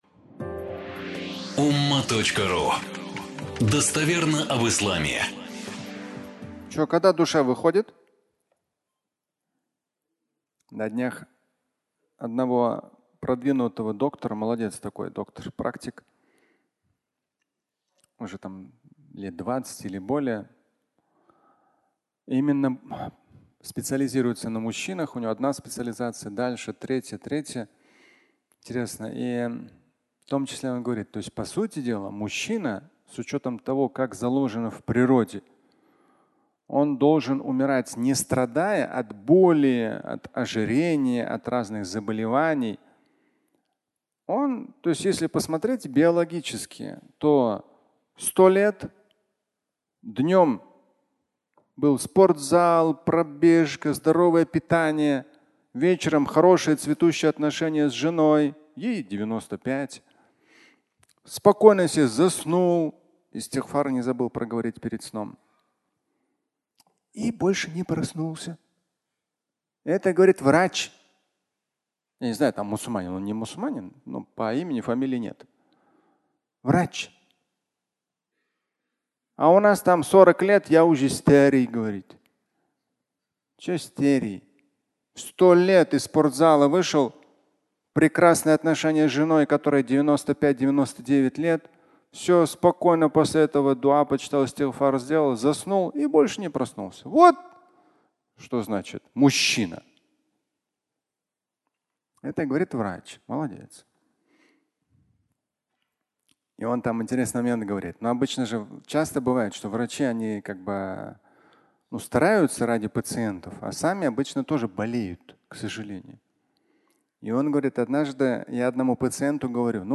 Фрагмент пятничной лекции